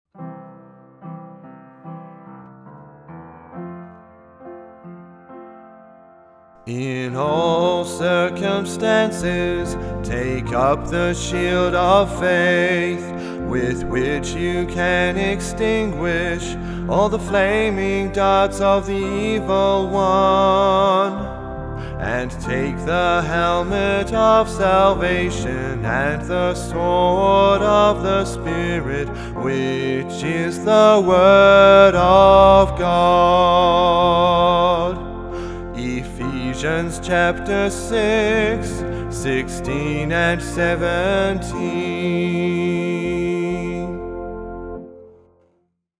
Vocalist
Piano
Keyboard